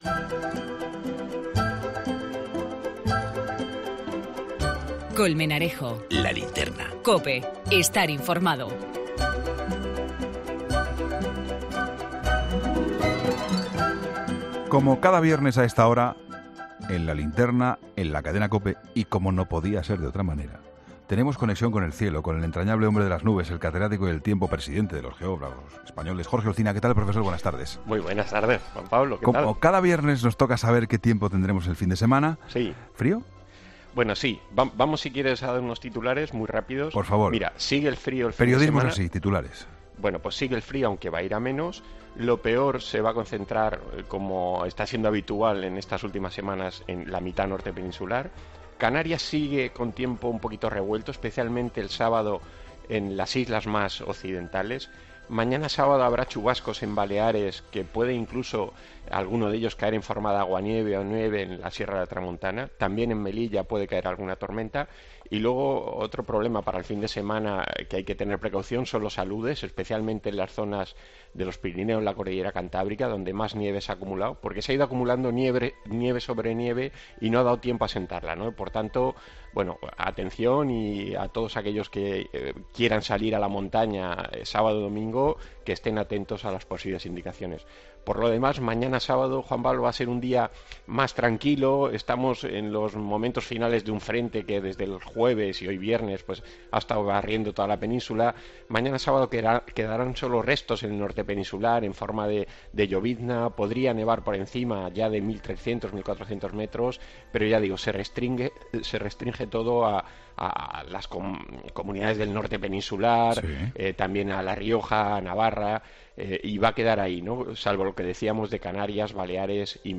PRONÓSTICO DEL TIEMPO EN 'LA LINTERNA'